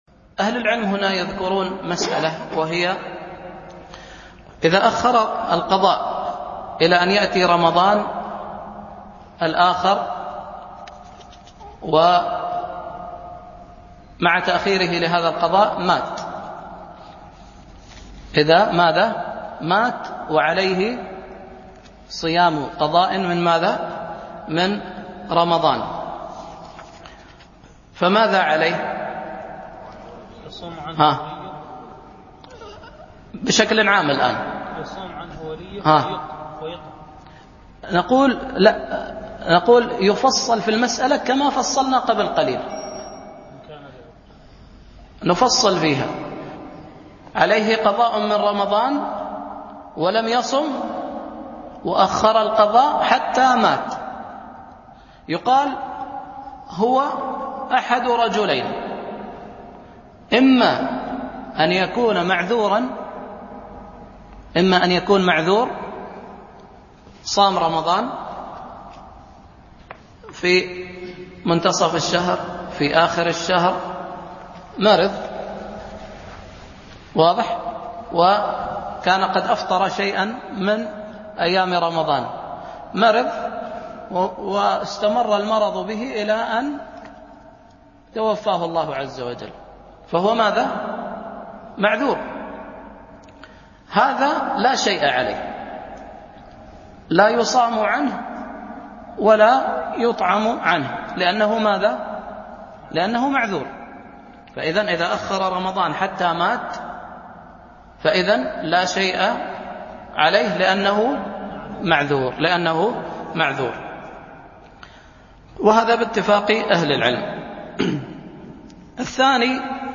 التفصيل في حكم من مات وعليه قضاء الألبوم: دروس مسجد عائشة (برعاية مركز رياض الصالحين ـ بدبي) المدة
التنسيق: MP3 Mono 22kHz 32Kbps (VBR)